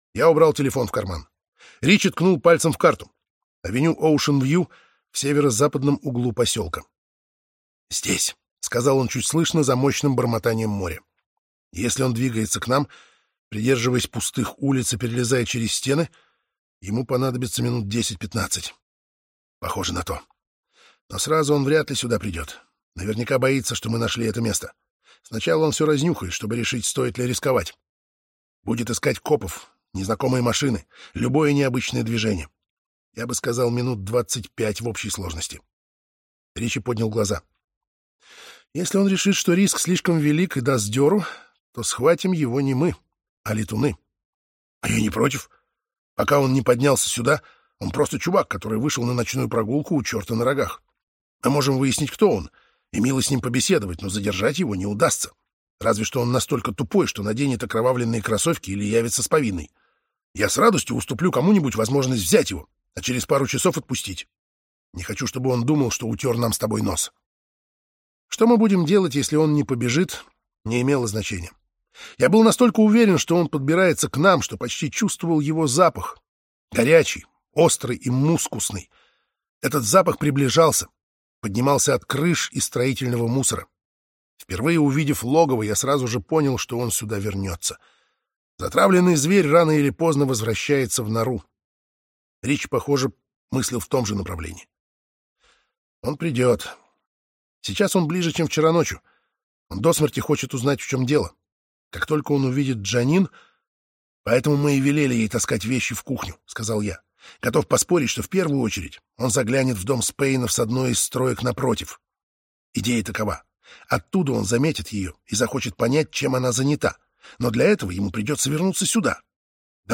Аудиокнига Брокен-Харбор | Библиотека аудиокниг